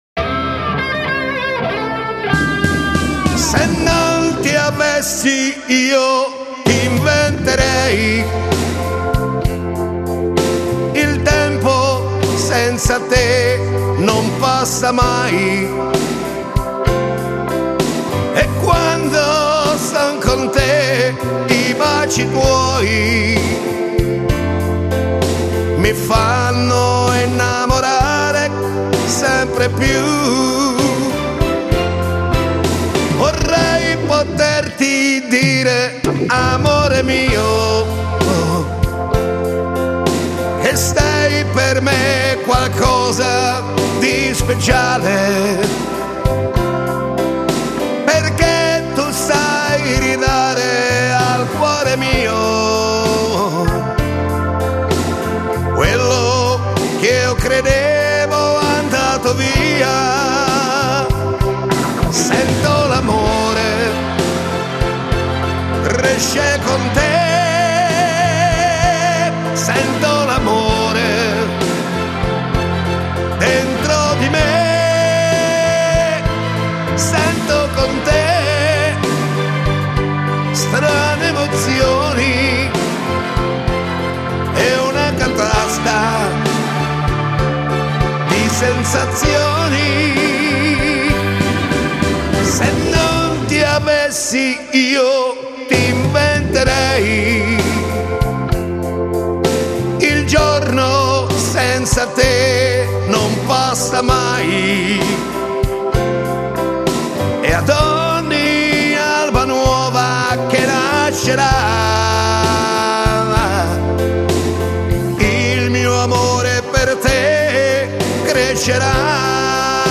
Genere: Terzinato